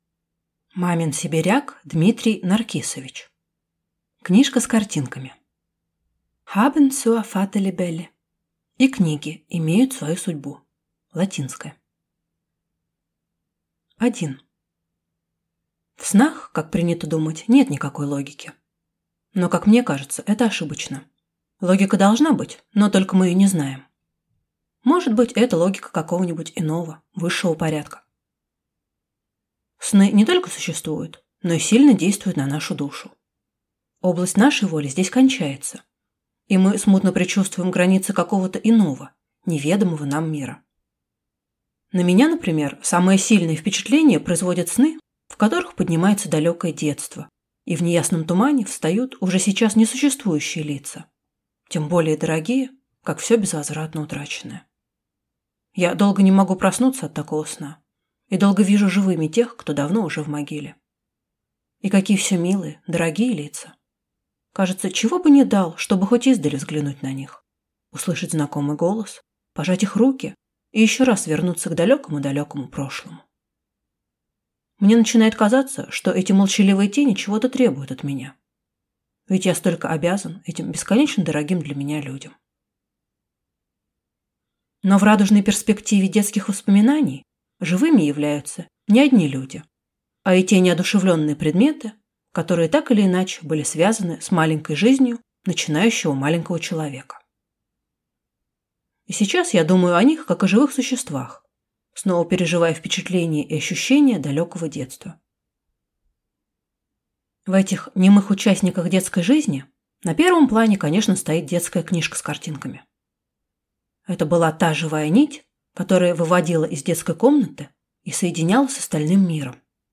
Аудиокнига Книжка с картинками